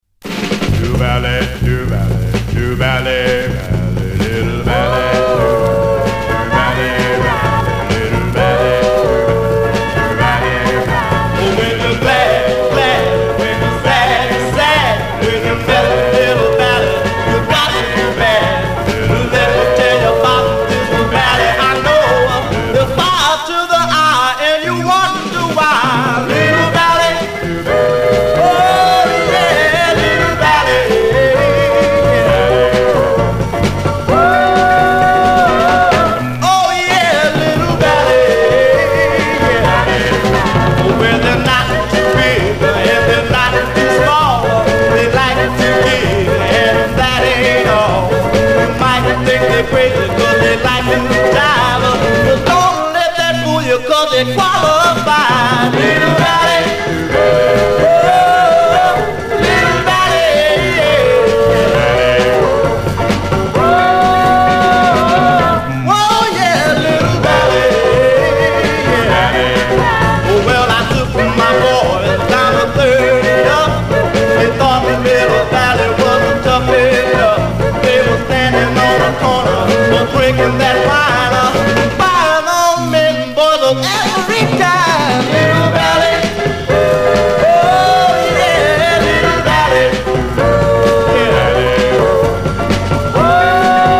SOUL, 60's SOUL, DOO WOP, OLDIES, 7INCH
モッドなR&Bダンサー